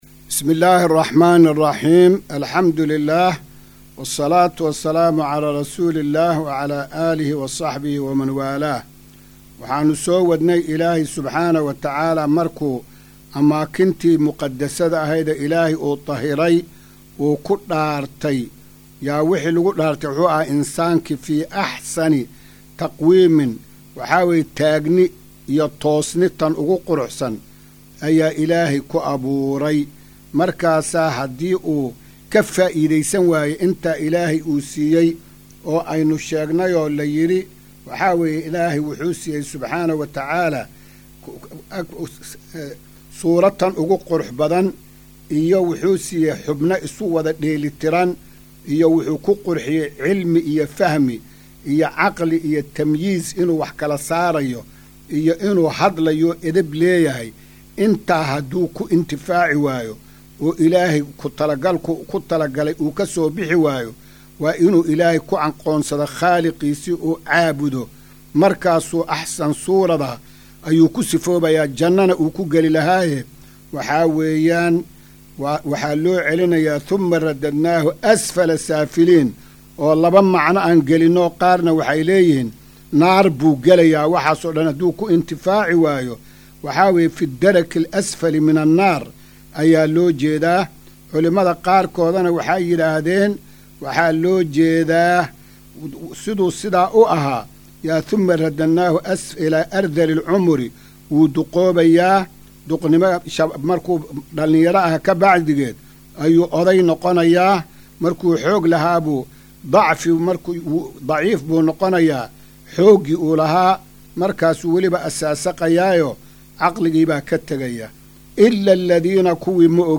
Maqal:- Casharka Tafsiirka Qur’aanka Idaacadda Himilo “Darsiga 288aad”